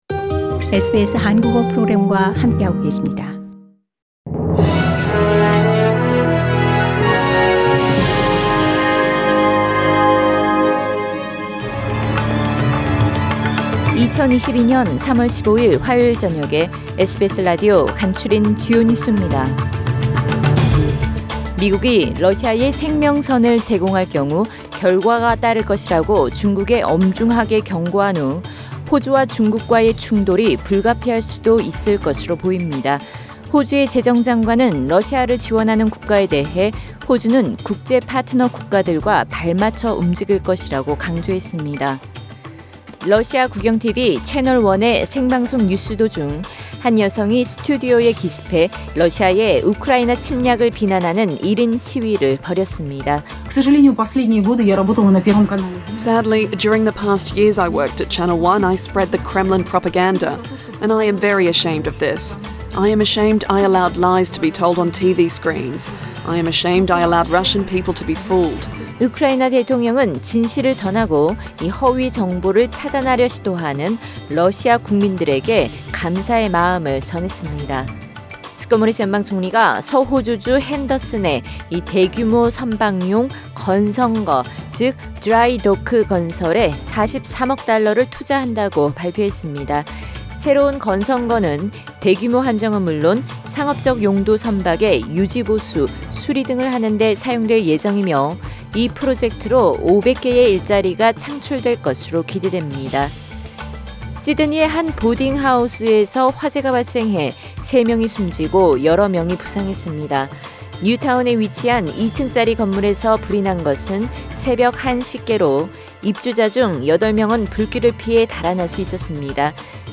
SBS News Outlines…2022년 3월 15일 저녁 주요 뉴스
2022년 3월 15일 화요일 저녁의 SBS 뉴스 아우트라인입니다.